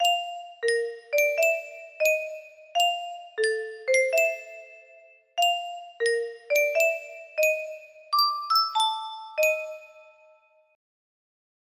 Faded Face music box melody
Just seeing how it sound when you spell things with the notes.